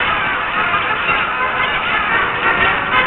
Monty Python Sample"というファイル名で雑踏の音源が存在していたそうです。